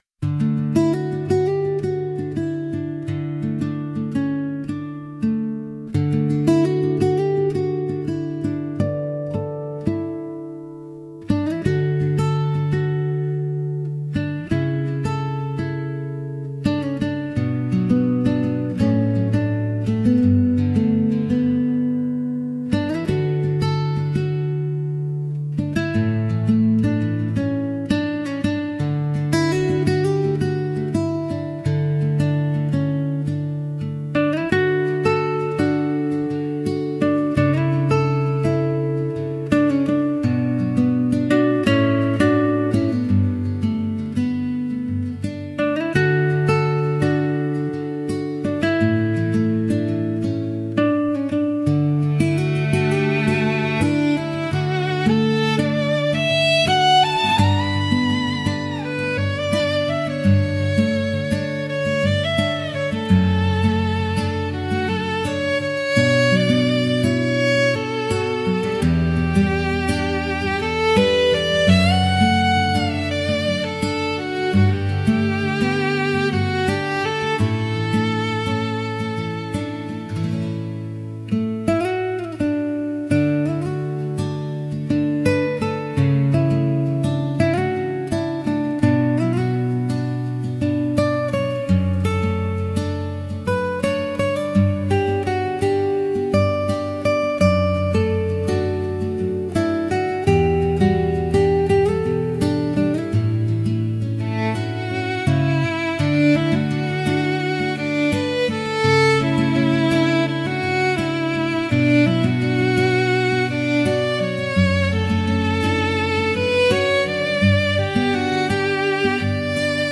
聴く人に安心感と癒しを与え、静かな集中や心の安らぎをサポートしながら、邪魔にならない背景音楽として活用されます。